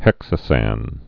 (hĕksə-săn)